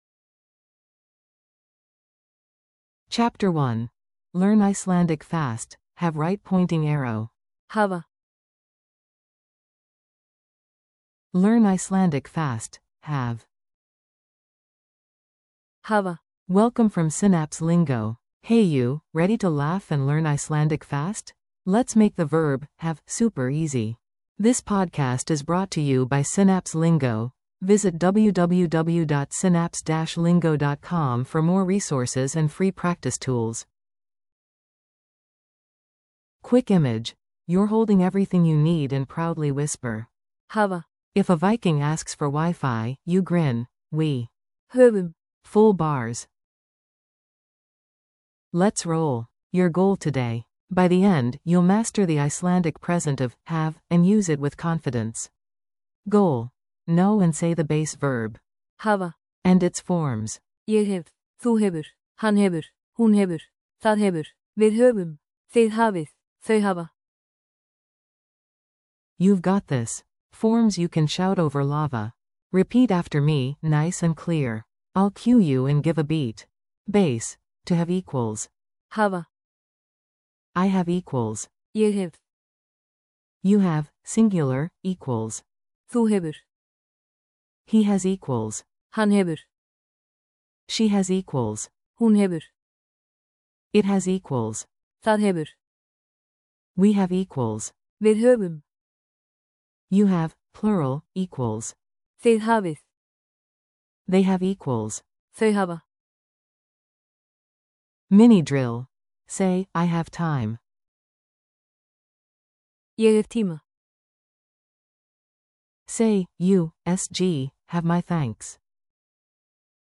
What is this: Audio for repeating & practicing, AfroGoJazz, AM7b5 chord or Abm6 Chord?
Audio for repeating & practicing